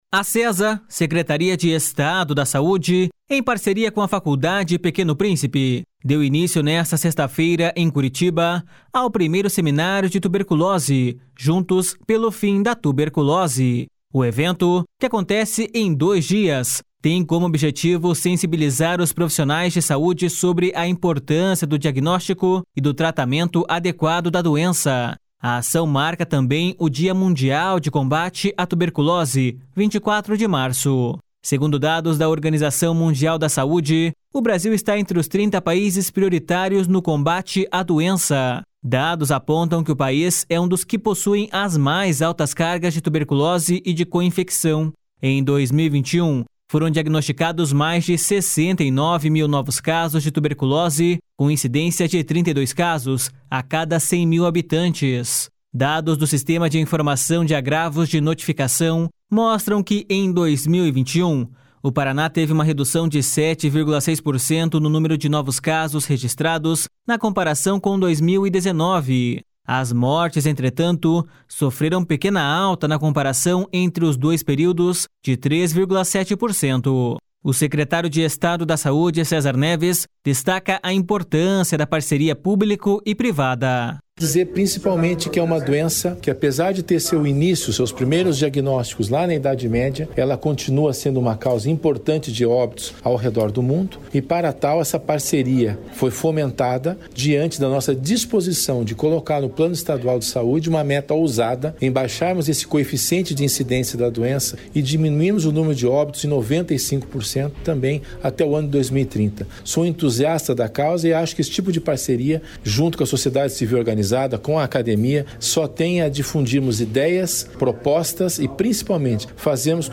O secretário de Estado da Saúde, César Neves, destaca a importância da parceria público e privada.// SONORA CÉSAR NEVES.//